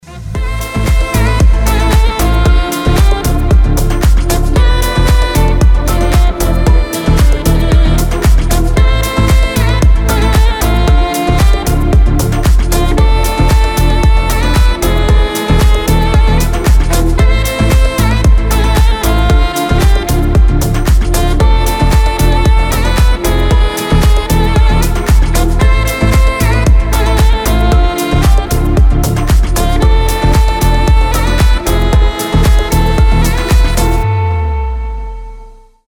красивые
без слов
Саксофон
New Age
Чудесная композиция с солирующим саксофоном